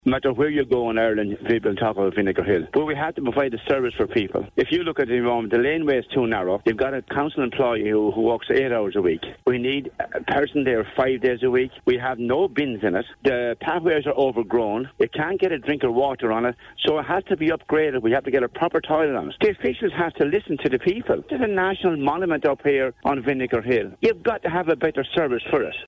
Speaking earlier on Morning Mix Councillor Owens is calling on Wexford County Council to listen to the people and provide proper services in the area